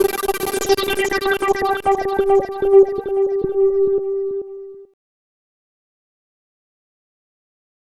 Session 08 - LFO FX.wav